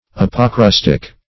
Search Result for " apocrustic" : The Collaborative International Dictionary of English v.0.48: Apocrustic \Ap`o*crus"tic\, a. [Gr.